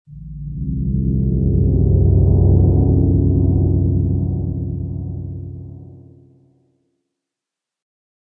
Звуки пещер из Майнкрафт
Находясь в пещерах Майнкрафт можно услышать множество разных тревожных и порой даже пугающих звуков.
Проклятая арфа